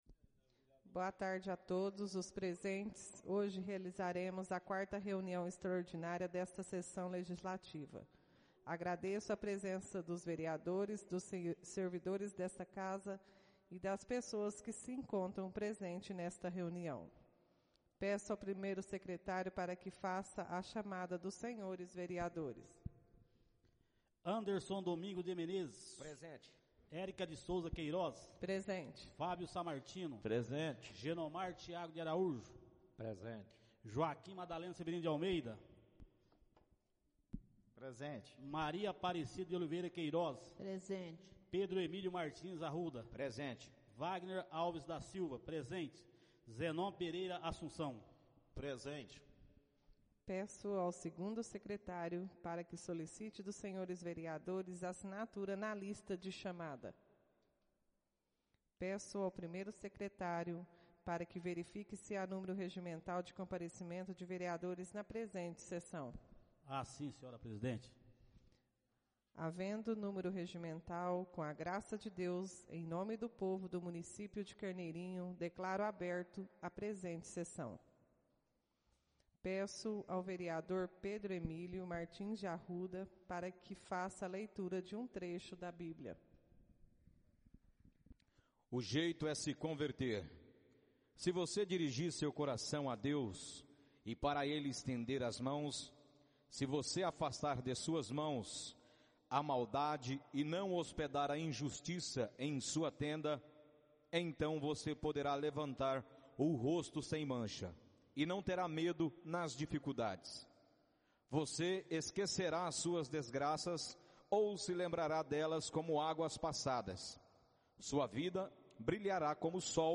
Áudio da 04.ª reunião extraordinária de 2022, realizada no dia 13 de junho de 2022, na sala de sessões da Câmara Municipal de Carneirinho, Estado de Minas Gerais.